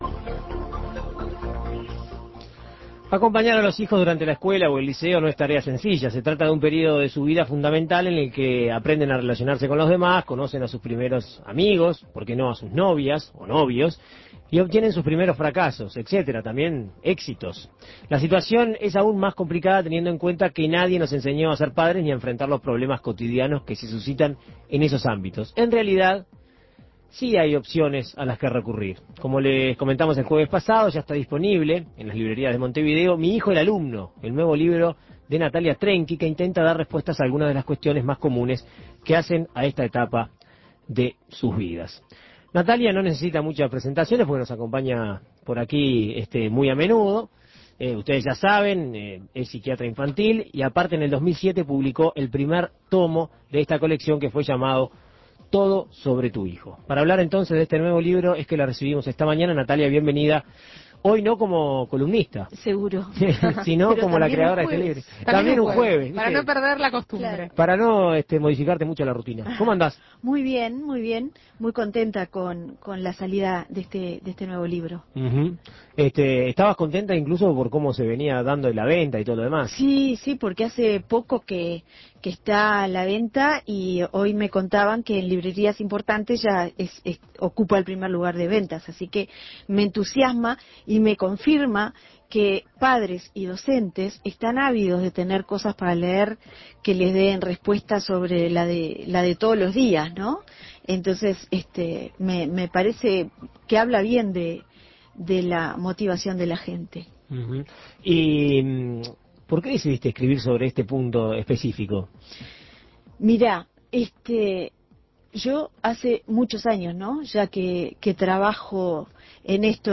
En Perspectiva Segunda Mañana dialogó con la psiquiatra infantil para conocer a qué preguntas intenta dar respuesta en su nuevo trabajo.